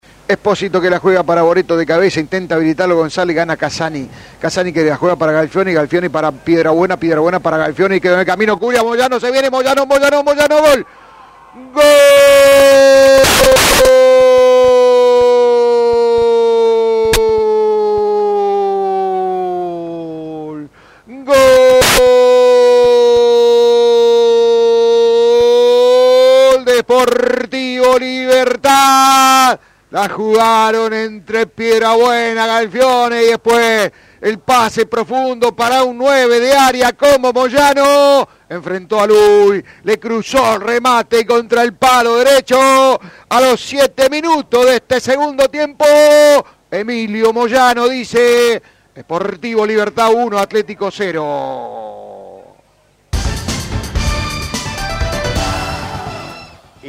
GOLES: